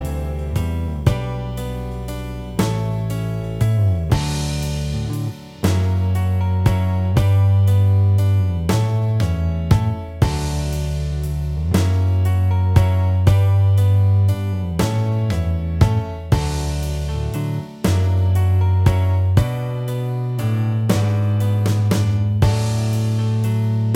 Minus Guitars Rock 4:07 Buy £1.50